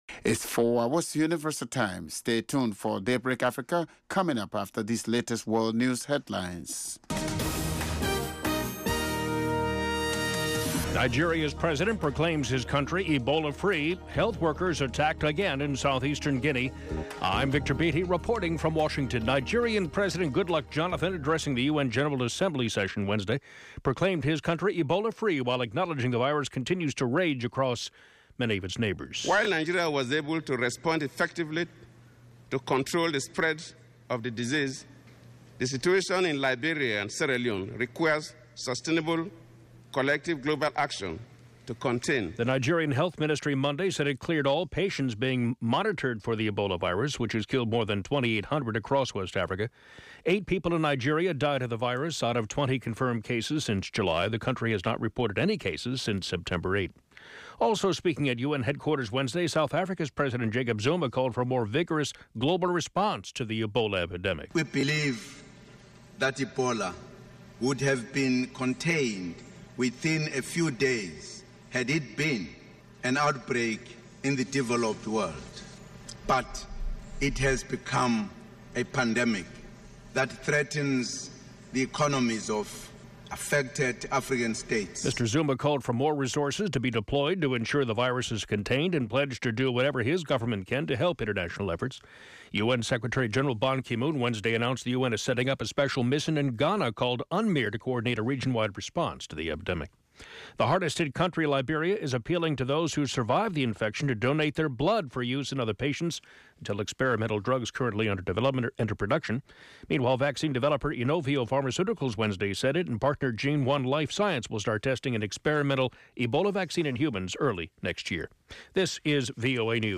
Each morning, Daybreak Africa looks at the latest developments on the continent, starting with headline news and providing in-depth interviews, reports from VOA correspondents, sports news as well as listener comments.